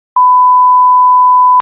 Censor Beep Sound Button: Unblocked Meme Soundboard